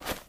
High Quality Footsteps
STEPS Dirt, Run 07.wav